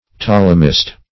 Ptolemaist \Ptol"e*ma`ist\, n. One who accepts the astronomical system of Ptolemy.